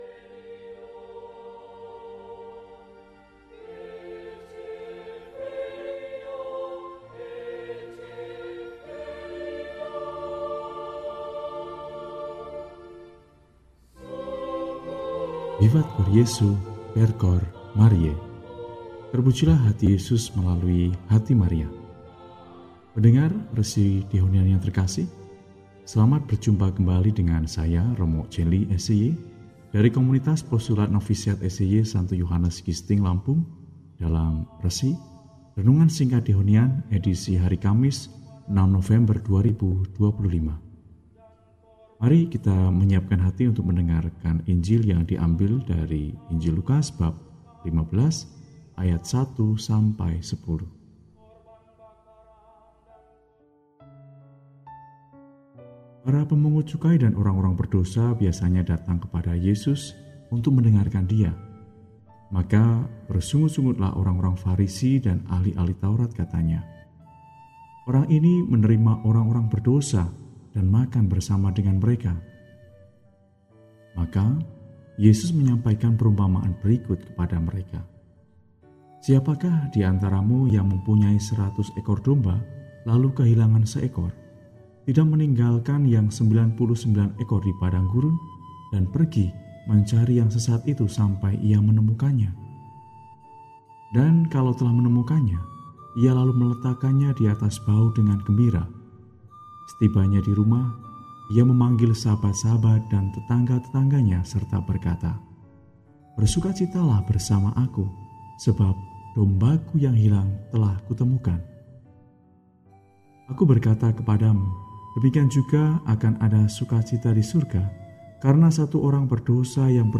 Kamis, 06 November 2025 – Hari Biasa Pekan XXXI – RESI (Renungan Singkat) DEHONIAN